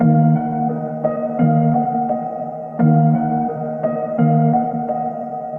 LOOP - TIMELESS.wav